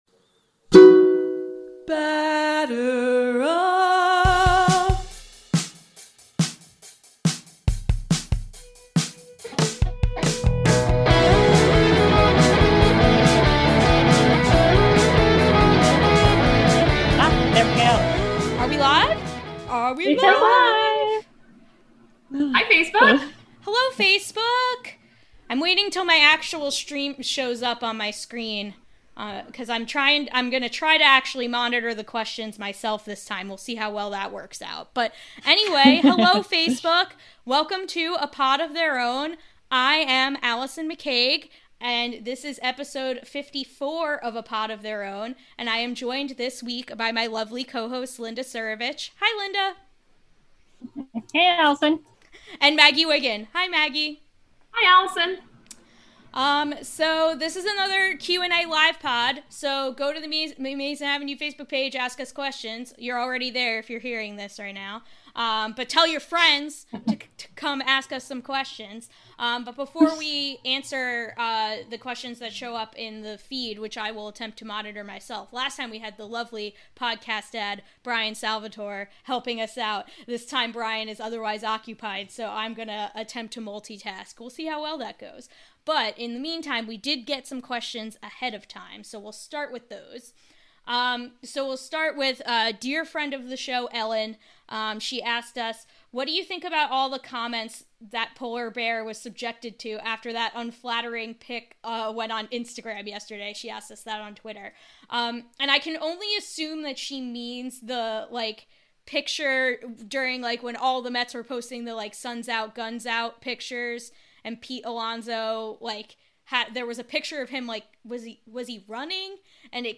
Welcome back to A Pod of Their Own, a show by the women of Amazin’ Avenue where we talk all things Mets, social justice issues in baseball, and normalize female voices in the sports podcasting space.
This week, we return to Facebook Live to take more questions from Twitter, email, and the comments sections. We talk Mets DH, Opening Day plans, Yasiel Puig fandom, and more.